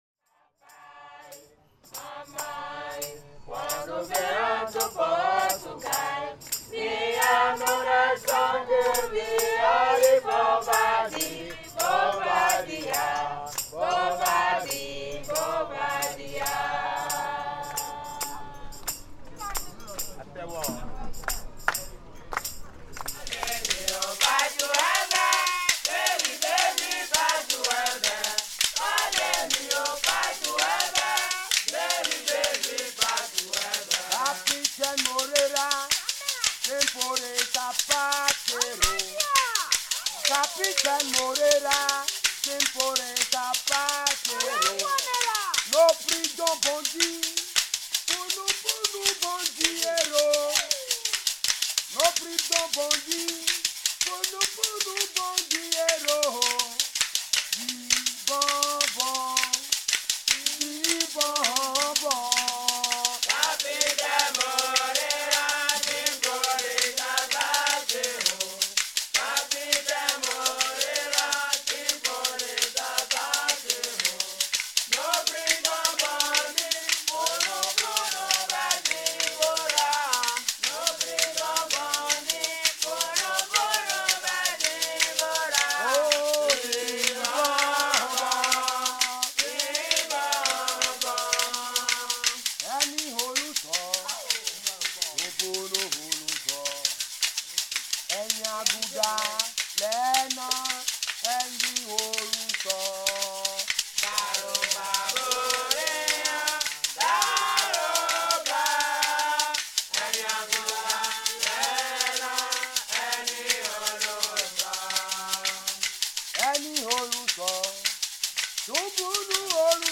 The photographs and sound recordings from this journey show the importance of the Brazilian cultural heritage in the country - mainly in the cities of Porto-Novo and Ouidah - and the extent to which, after centuries, it still feeds into the Beninese reality in a very visible way.